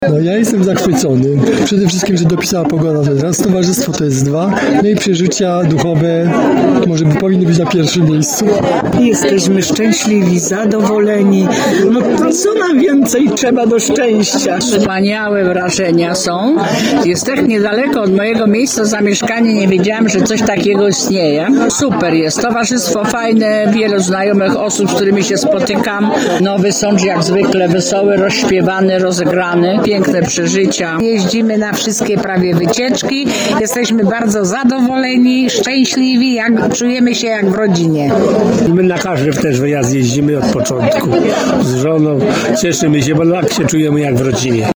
Członkowie tarnowskiego i sądeckiego Klubu Dobrze Nastawionych zjechali się do malowniczej Woli Lubeckiej w gminie Ryglice, gdzie w rodzinnych Piwnicach Antoniego odbyła się coroczna majówka… a właściwie 'czerwcówka”.